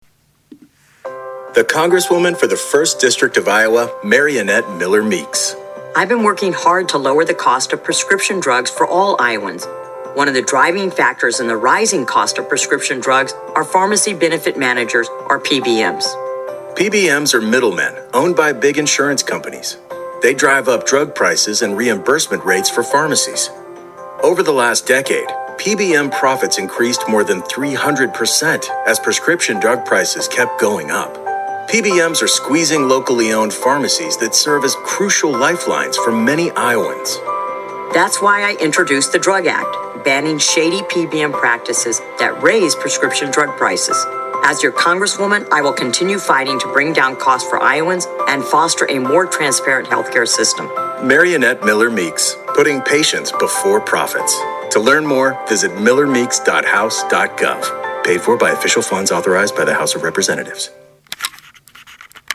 To a casual listener, the spot would sound like a campaign commercial. Near the end, a male voice-over said, “Mariannette Miller-Meeks. Putting patients before profits.”